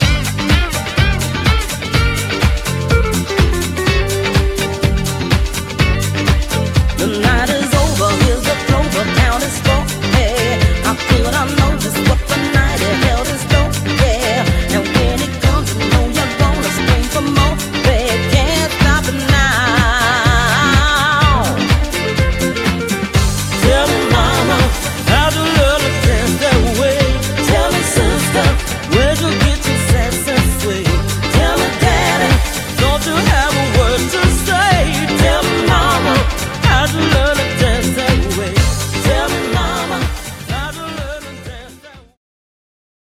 A VOICE MADE OF GOLD!!"